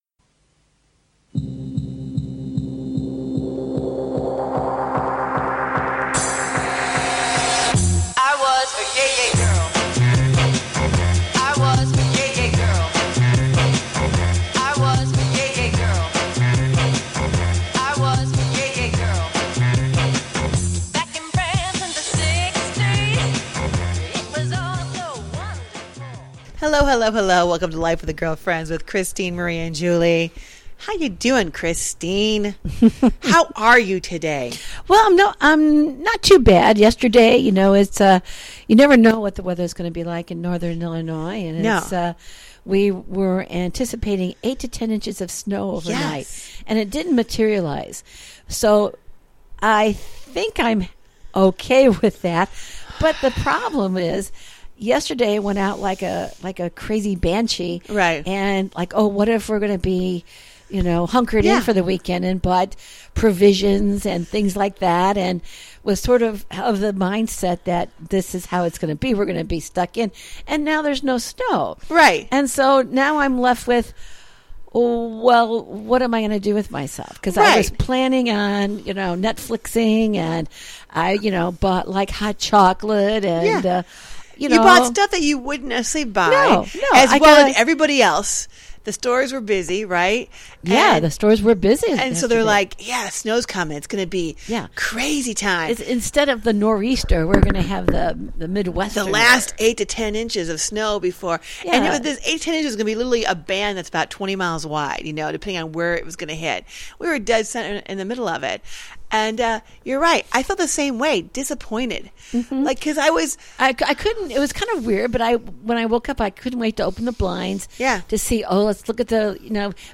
This mother/daughter coaching duo shares their everyday thoughts on relationships, family, hot topics and current events, and anything that tickles their fancy with warmth, wit, and wisdom.
And join the girlfriends up close and personal for some daily chat that’s humorous, wholesome, and heartfelt.